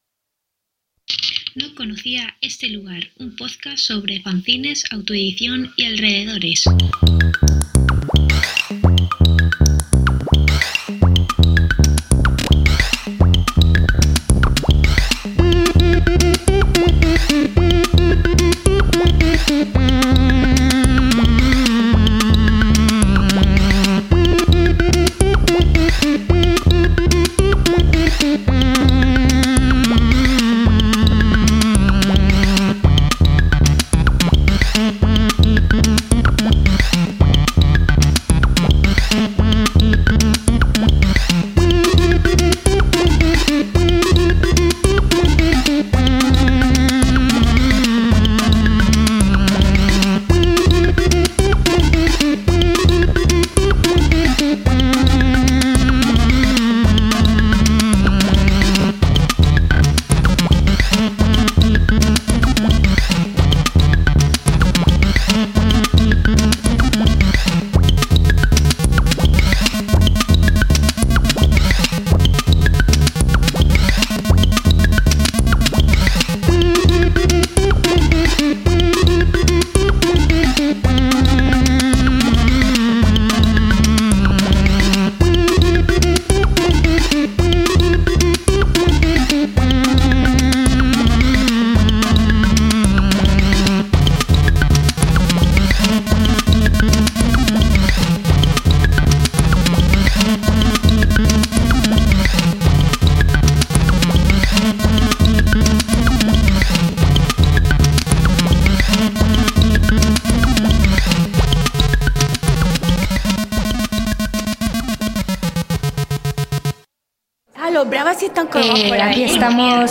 5cc350947d945b6ce427500b794b6072f74c3c66.mp3 Títol Ràdio Web MACBA Emissora Ràdio Web MACBA Titularitat Tercer sector Tercer sector Cultural Nom programa No conocía este lugar Descripció Careta del programa. Espai fet en directe des del Museu d’Art Contemporani de Barcelona (MACBA), amb motiu de la inauguració de l'exposició de Gelen Jeleton